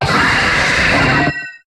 Cri de Palkia dans Pokémon HOME.